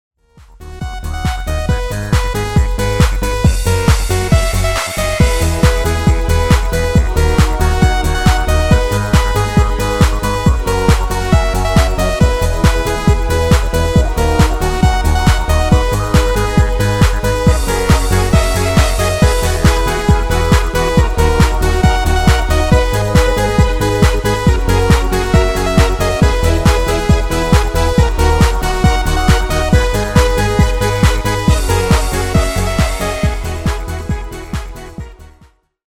Demo/Koop midifile
- GM = General Midi level 1
- Géén vocal harmony tracks
Demo's zijn eigen opnames van onze digitale arrangementen.